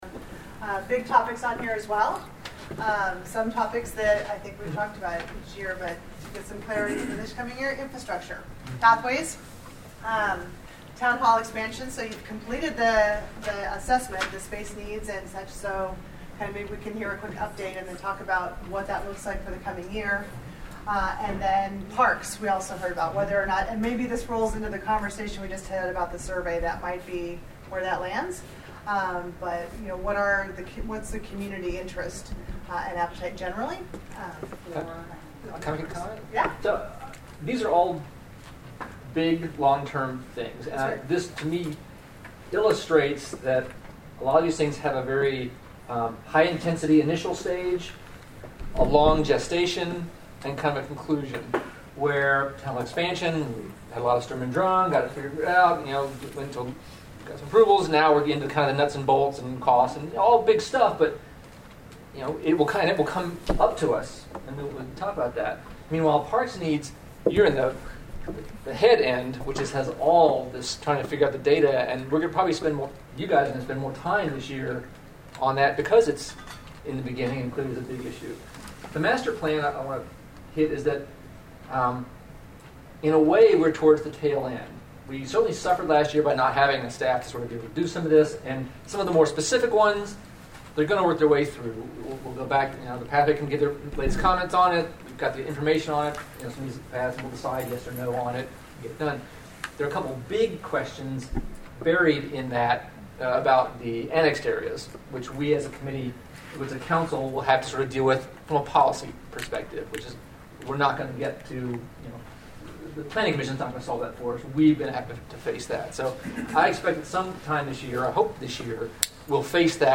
At the January 2020 LAH Council meeting goal setting event, the LAH council discussed various topics and projects related to 5G, Fiber and Gigabit internet in Los Altos Hills.
Click to listen to LAH Council Jan 2020 goal setting meeting (MP3 File)